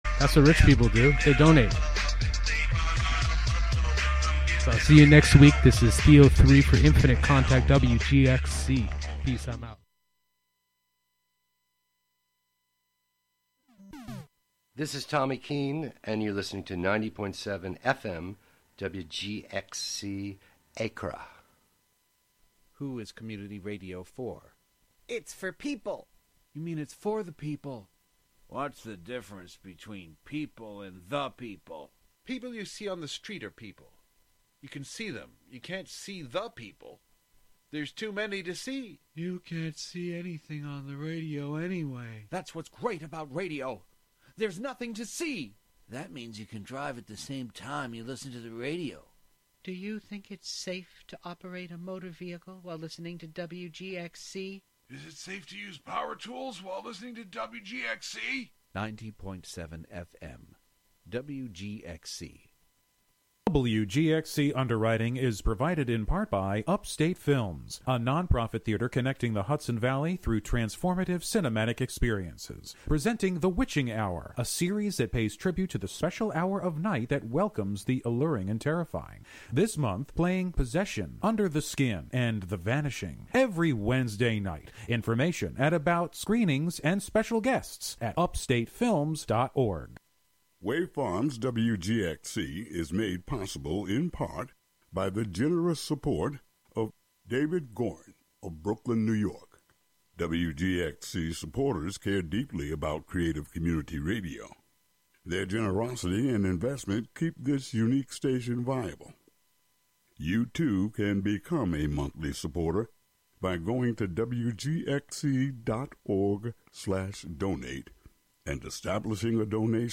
Hosted by various WGXC Volunteer Programmers.
Tune in for special fundraising broadcasts with WGXC Volunteer Programmers!